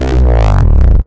Desecrated bass hit 15.wav